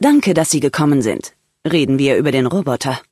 Beschreibung Lizenz Diese Datei wurde in dem Video-Spiel Fallout: New Vegas aufgenommen oder stammt von Webseiten, die erstellt und im Besitz von Bethesda Softworks oder Obsidian Entertainment sind, deren Urheberrecht von Bethesda Softworks oder Obsidian Entertainment beansprucht wird.